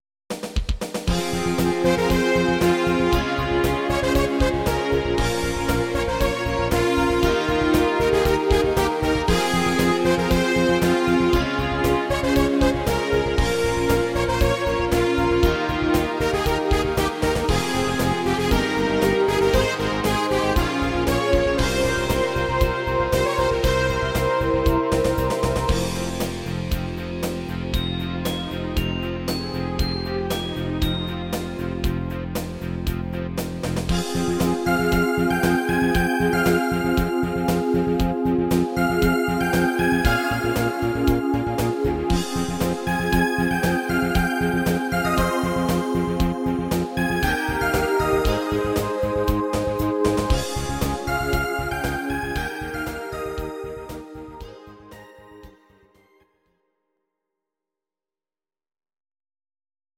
Synthesizer Version